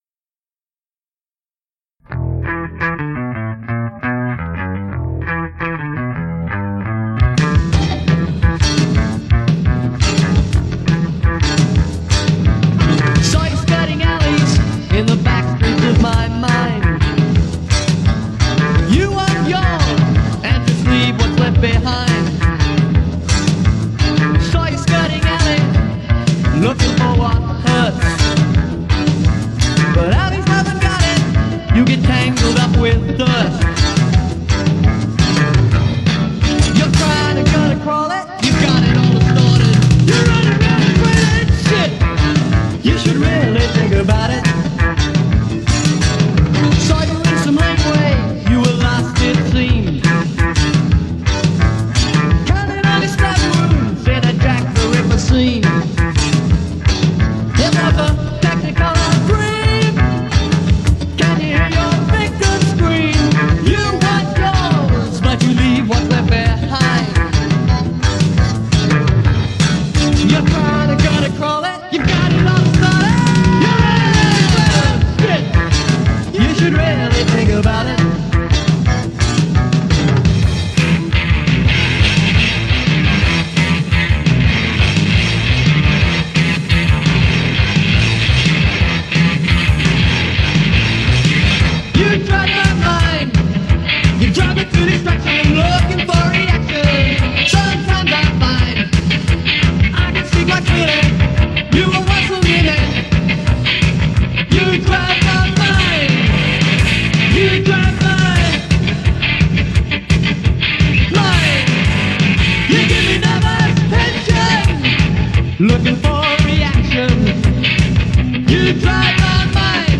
lead vocals/bass/keyboards
guitars/vocals/harmonica